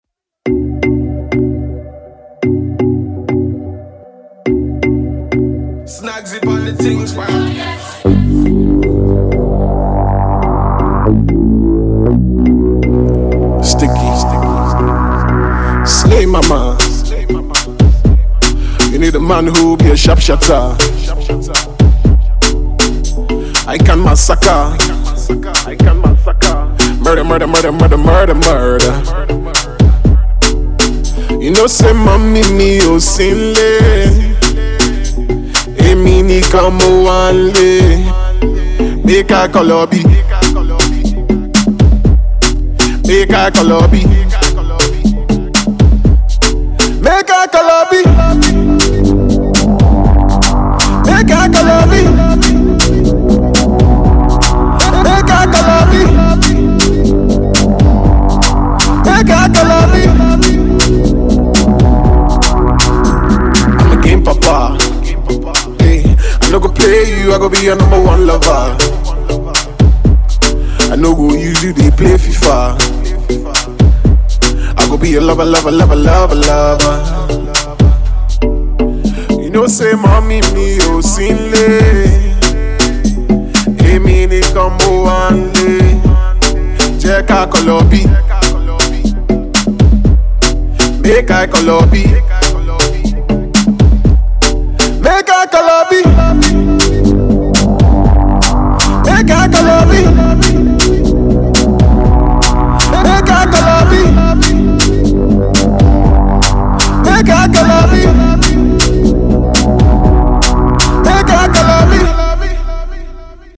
Home Afro-pop Audio
Afro fusion